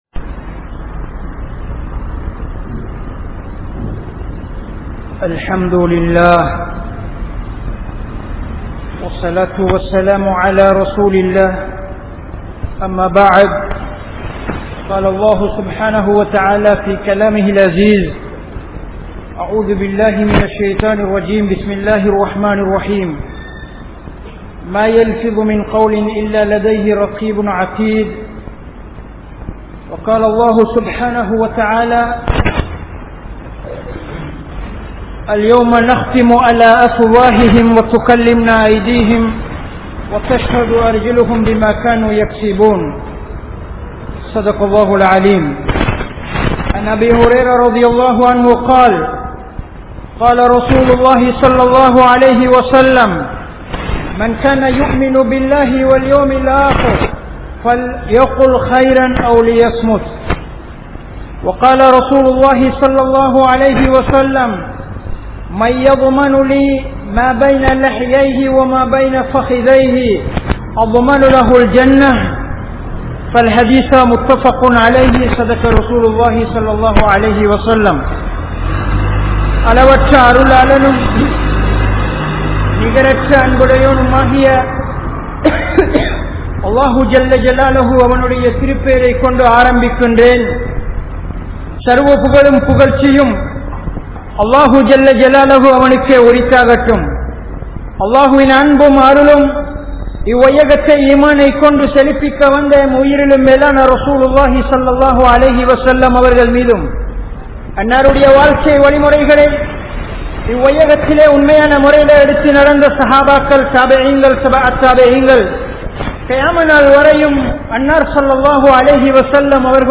Ganniyamaana Padaippu Manithan (கண்ணியமான படைப்பு மனிதன்) | Audio Bayans | All Ceylon Muslim Youth Community | Addalaichenai
Galle, Dangadera, Badr Jumua Masjith